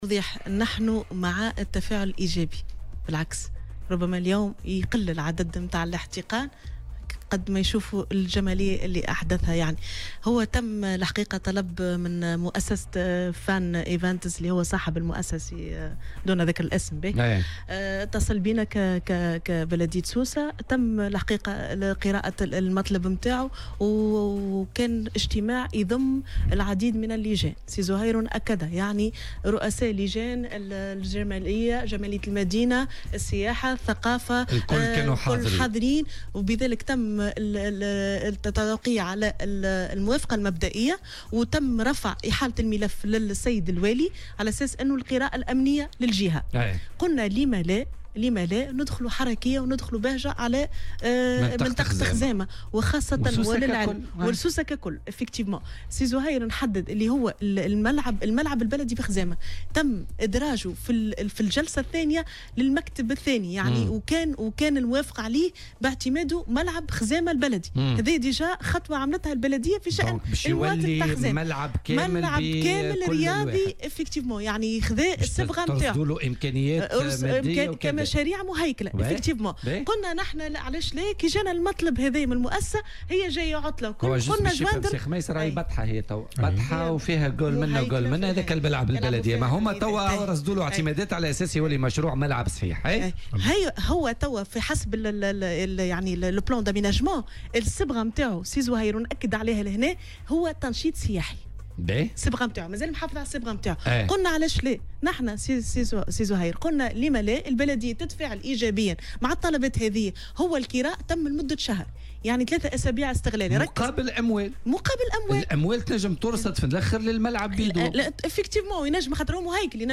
وأضافت في مداخلة لها اليوم في برنامج "بوليتيكا" أنه تم عرض الموضوع منذ البداية على مختلف اللجان (جمالية المدينة و السياحة والثقافة) وتم التوقيع على الموافقة الأولية ورفع الملف إلى والي الجهة، مشيرة إلى أن تركيز "السيرك" سيدخل حركية على المنطقة، وفق تعبيرها.